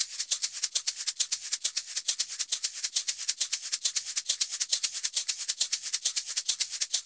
Shaker_Merengue 136-1.wav